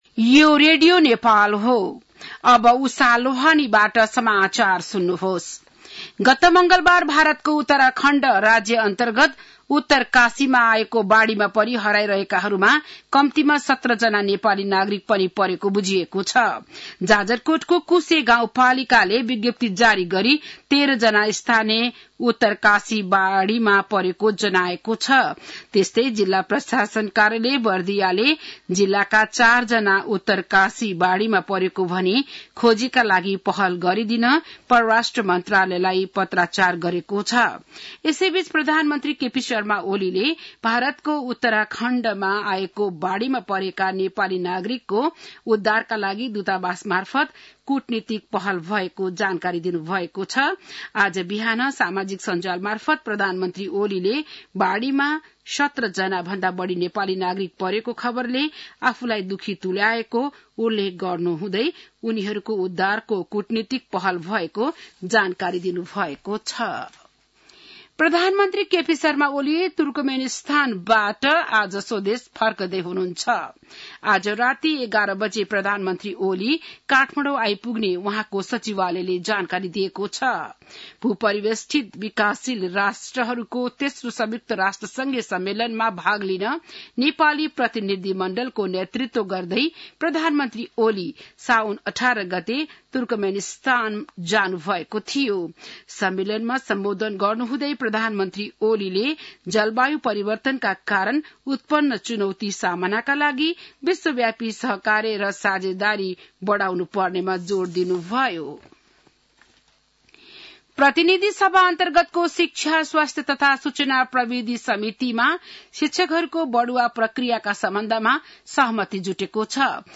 An online outlet of Nepal's national radio broadcaster
बिहान १० बजेको नेपाली समाचार : २३ साउन , २०८२